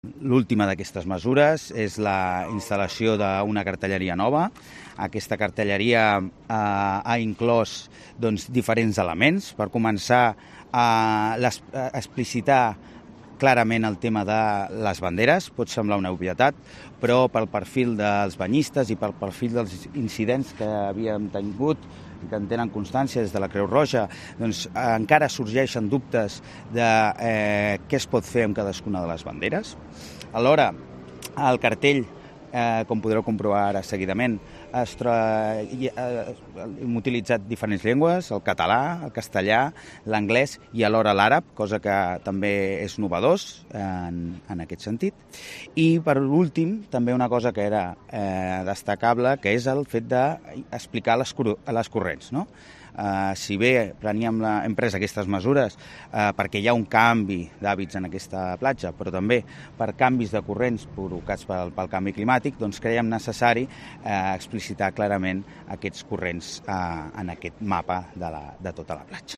Regidor de Medio Ambiente del Ayuntamiento de Tarragona, Guillermo, García